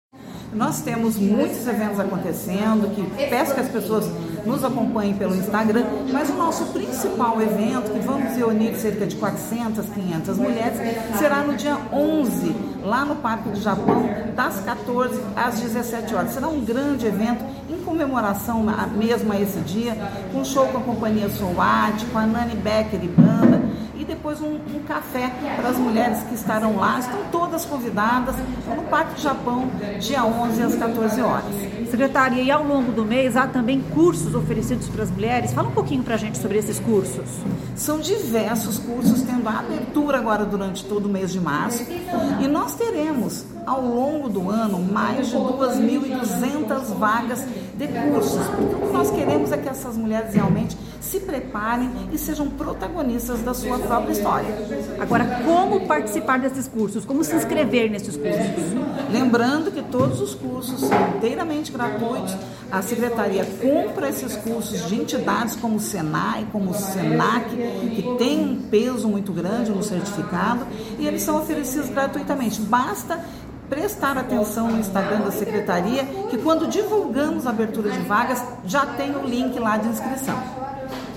É o que diz a secretária da Mulher, Olga Agulhon.